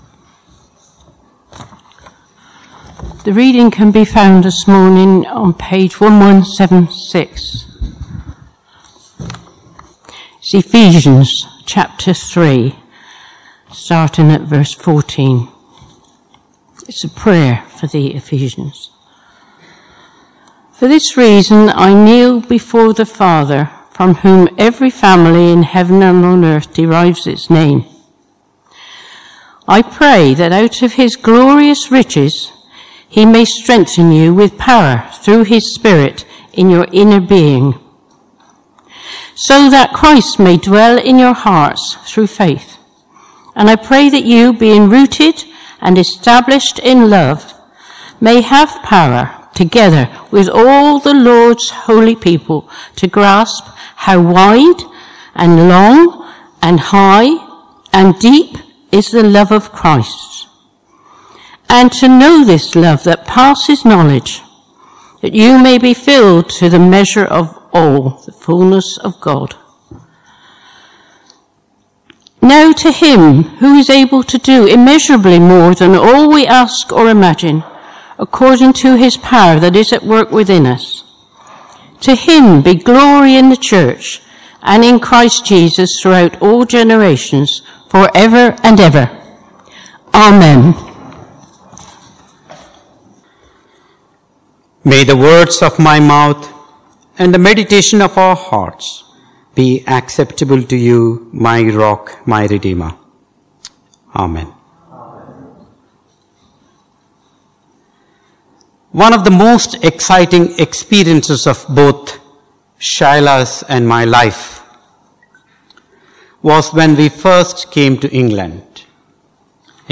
Ephesians Passage: Ephesians 3:14-21 Service Type: Morning Service Topics